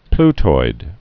(pltoid)